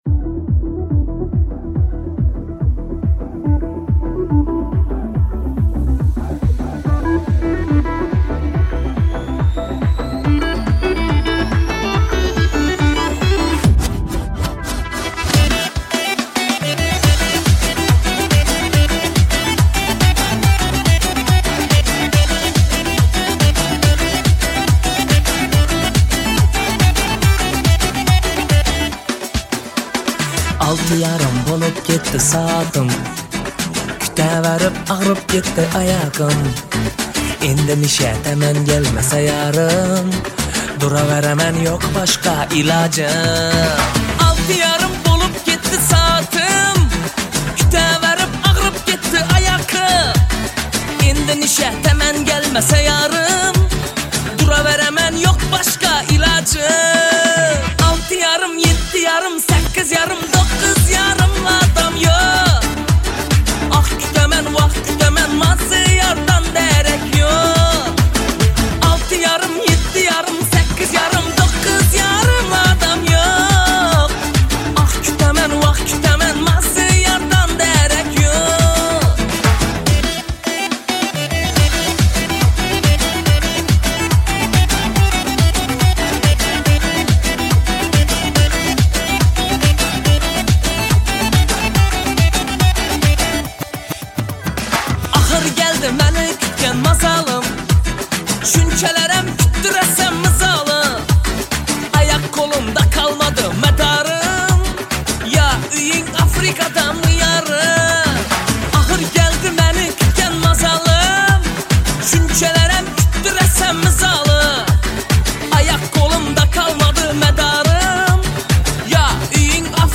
Узбекская музыка